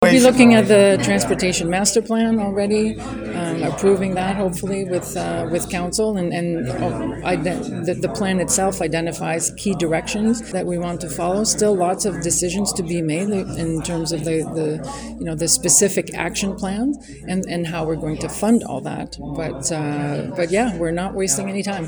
In an interview with myFM afterward, she emphasized the importance of thoughtful leadership on key priorities and promised a year of stability and clarity.